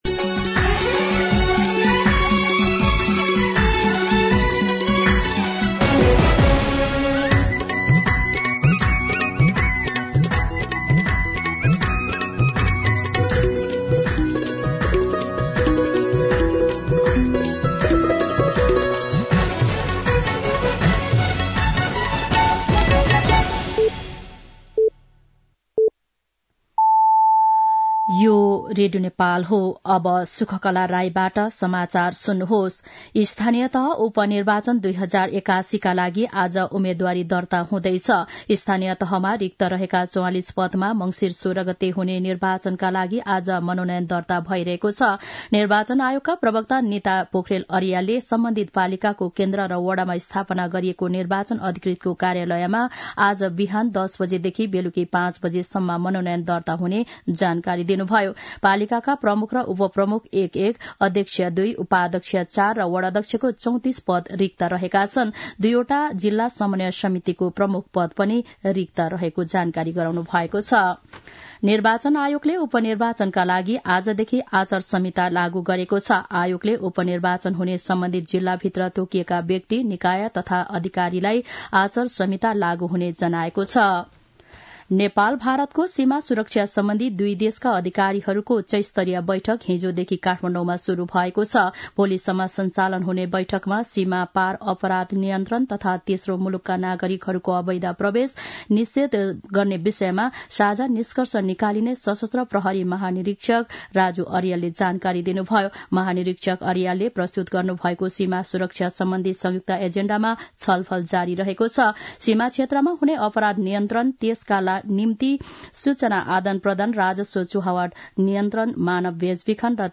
दिउँसो ४ बजेको नेपाली समाचार : ३ मंसिर , २०८१
4-pm-news-.mp3